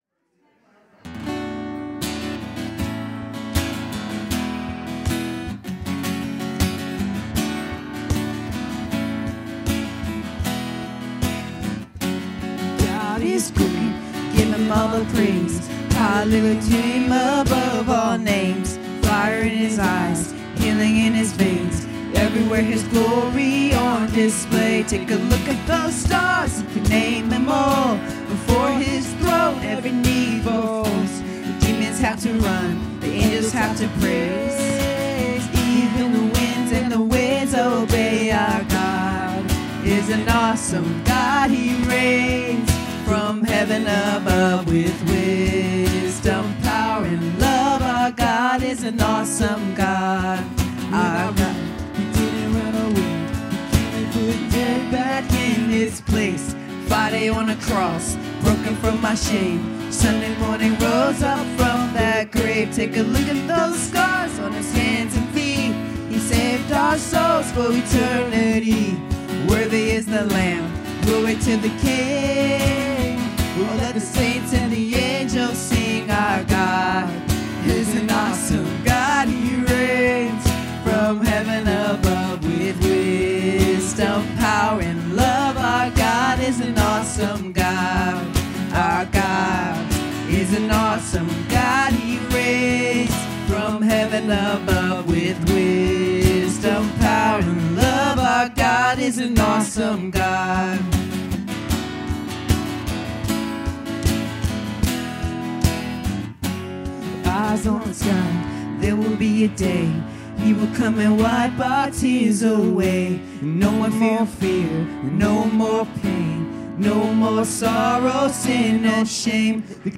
Worship 2025-10-19